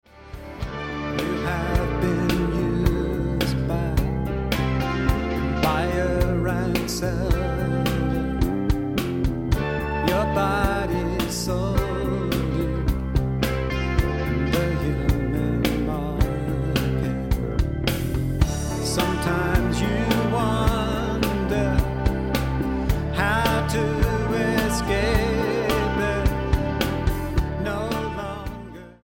Blues Album
Style: Blues